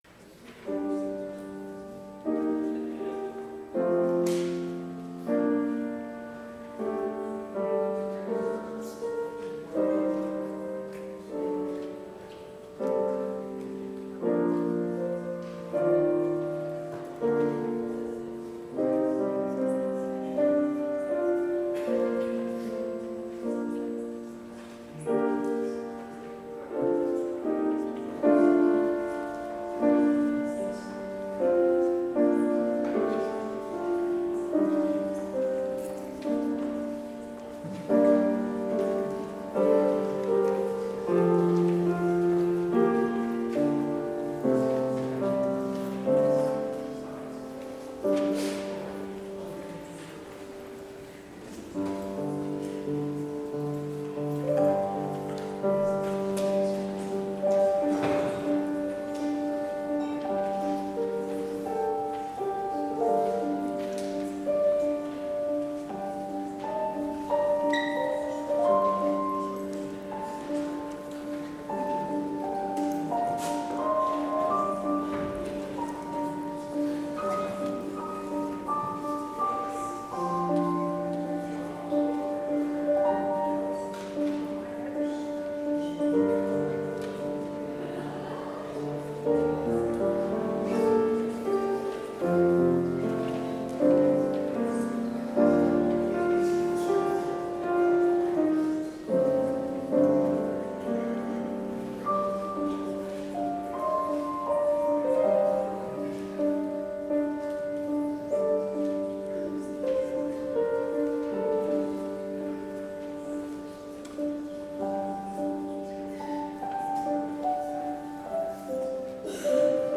Complete service audio for Chapel - December 15, 2021
Order of Service Prelude Hymn 123 - From Heav'n Above To Earth I Come